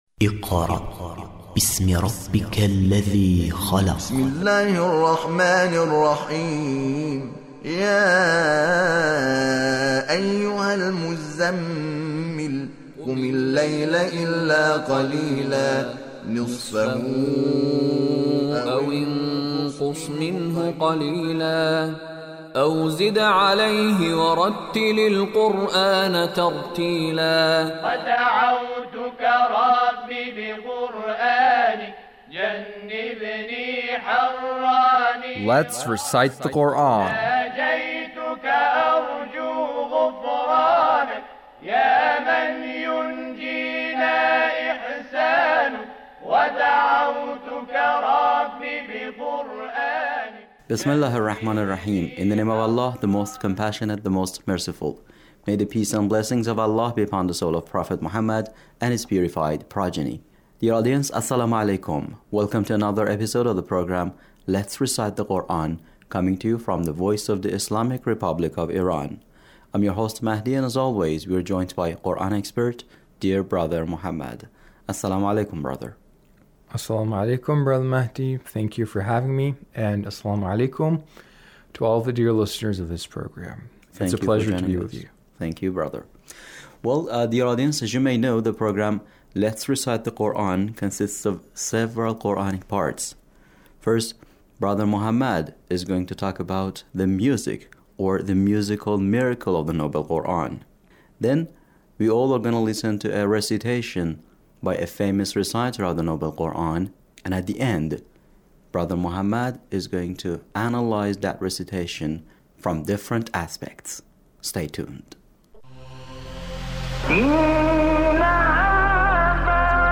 Recitation of Ragheb Mustafa Ghalwash